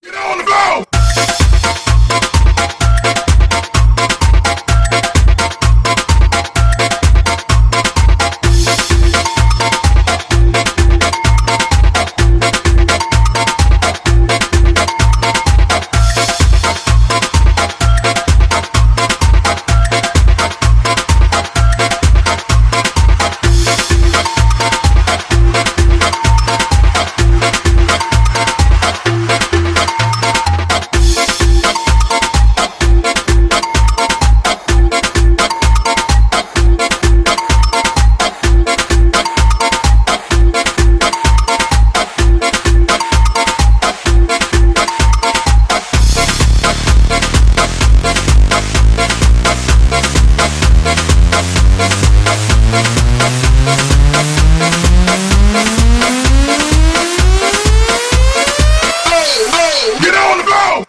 забавные
веселые